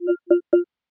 normal_lowbattery.ogg